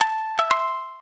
shamisen_aec1.ogg